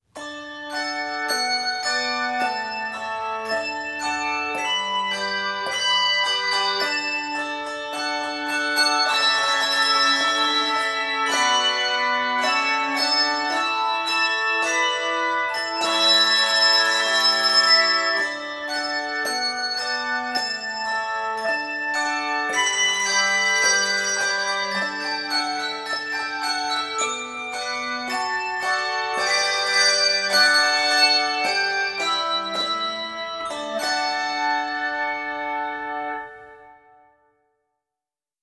Octaves: 4